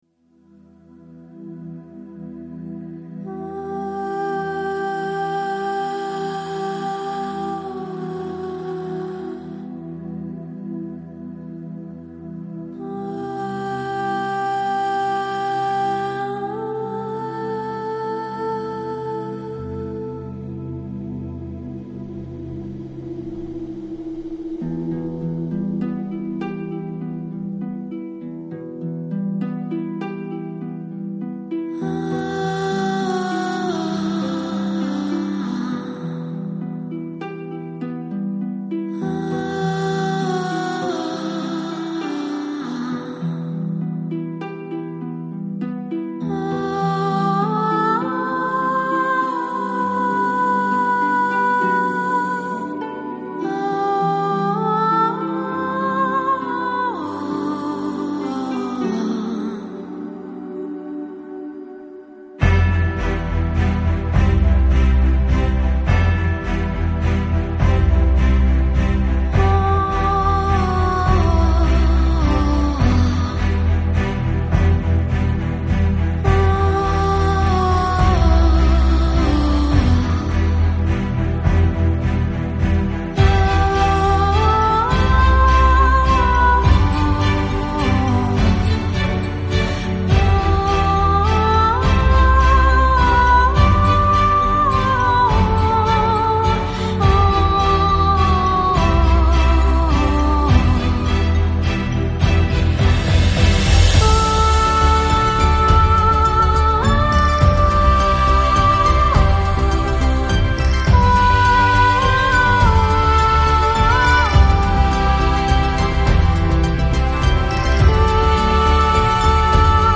Жанр: Downtempo